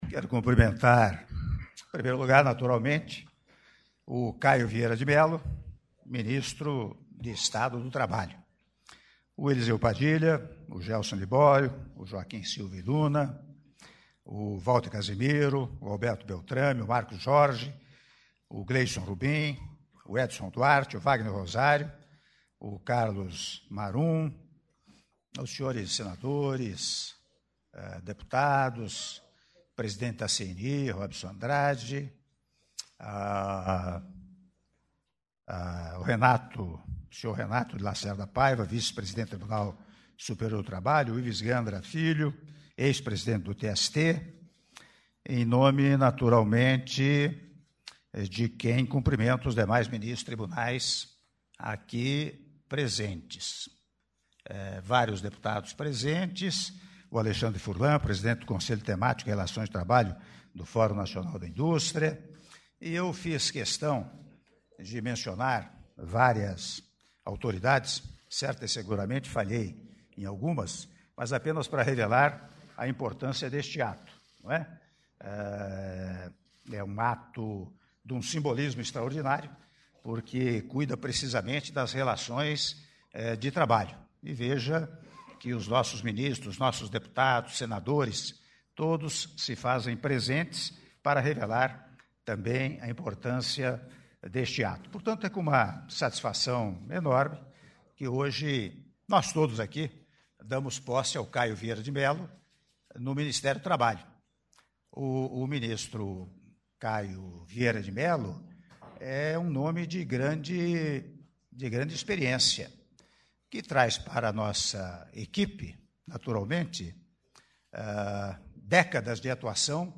Áudio do discurso do Presidente da República, Michel Temer, na Cerimônia de Posse do Ministro do Trabalho, Caio Luiz de Almeida Vieira de Mello - Palácio do Planalto (06min43s) — Biblioteca